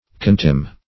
Contemn \Con*temn"\ (k[o^]n*t[e^]m"), v. t. [imp. & p. p.